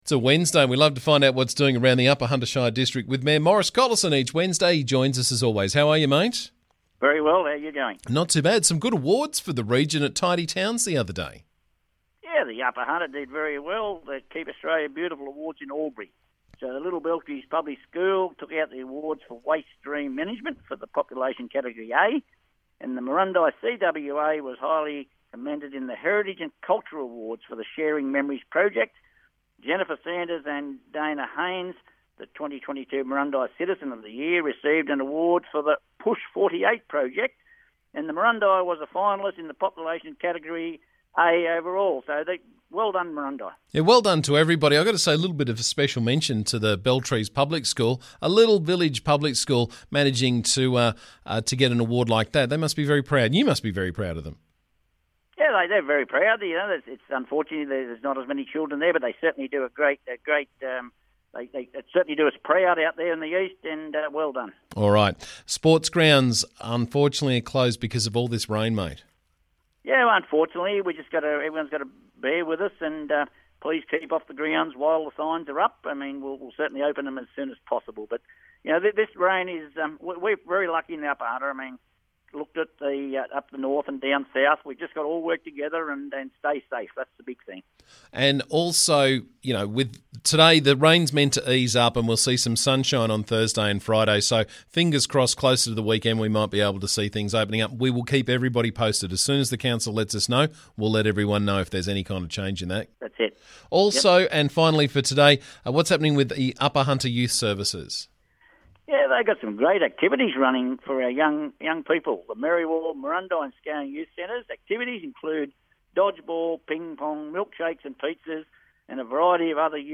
UHSC Mayor Maurice Collison was on the show this morning to keep us up to date with what's doing around the district.